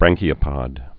(brăngkē-ə-pŏd)